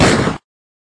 explosion-small.mp3